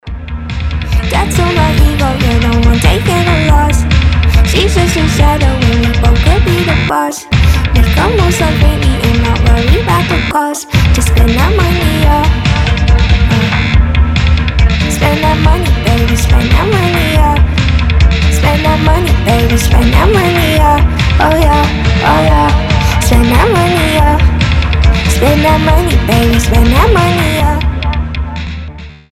• Качество: 320, Stereo
alternative